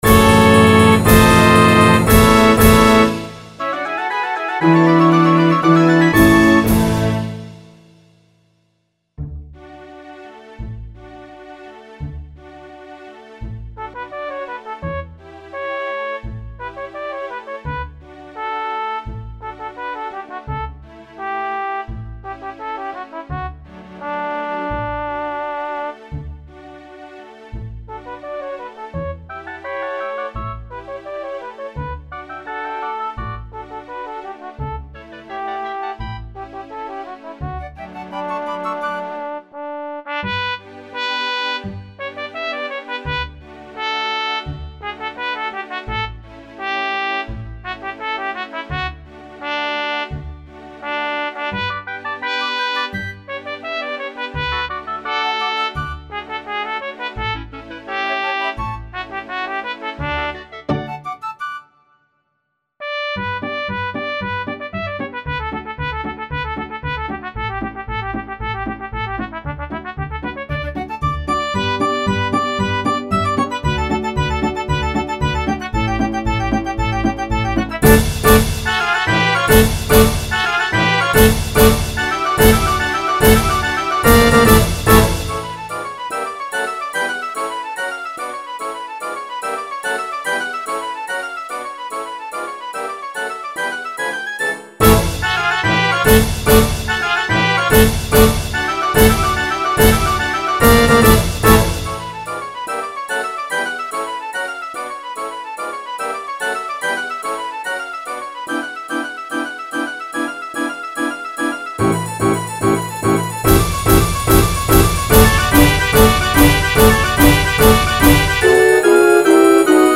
パート譜作成の基となったスコアの演奏です。